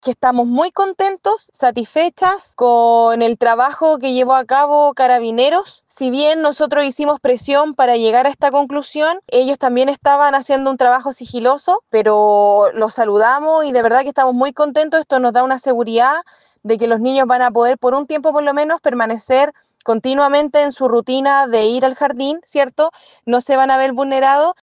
cu-detencion-ladron-jardin-vocera.mp3